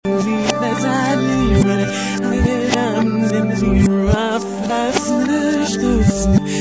(hint: it's reversed!)